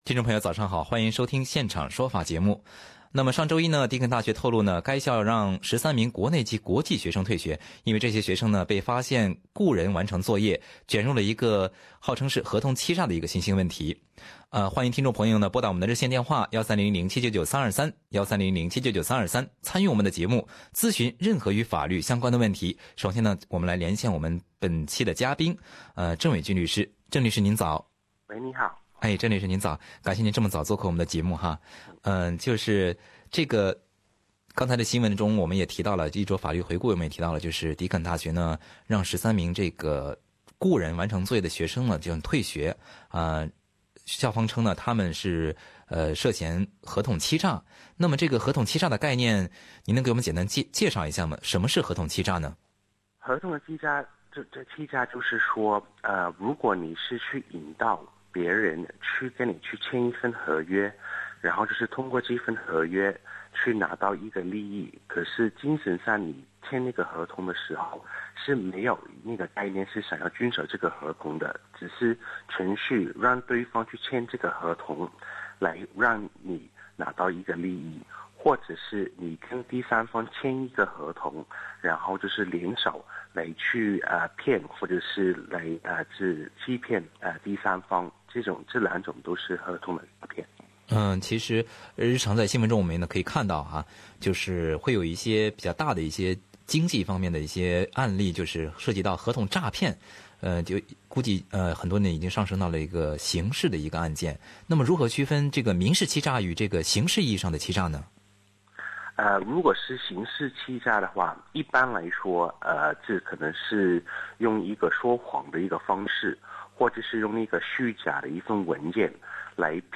本周一，迪肯大学透露称，该校已经让13名国内及国际学生退学，因为这些学生被发现雇人完成作业，涉嫌合同欺诈。今天的现场说法听众热线节目